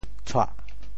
潮州发音 潮州 doh4 白 duêg4 文 cuah4 白
tshuah4.mp3